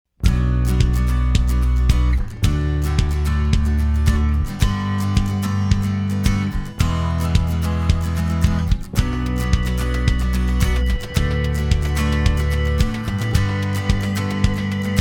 Easy Listening, Inspirational